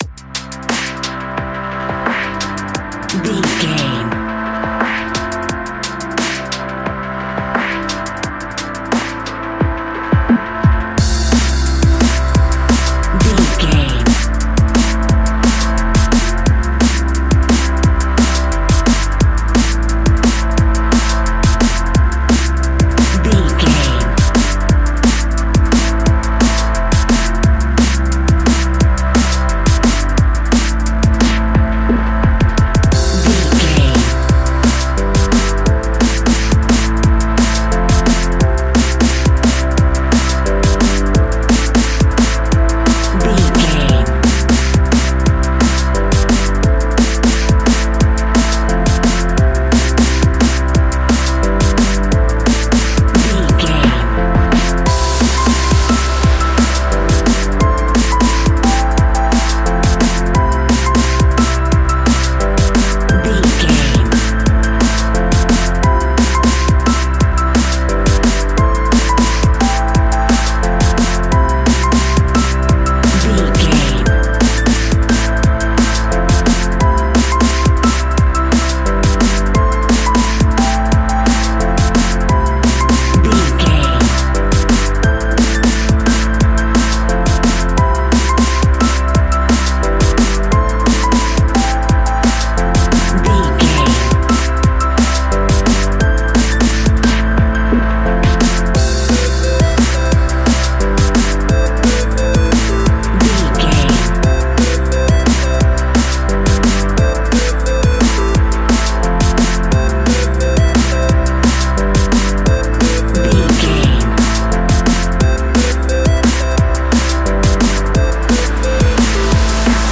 Ionian/Major
Fast
driving
uplifting
futuristic
hypnotic
drum machine
synthesiser
electric piano
sub bass
synth leads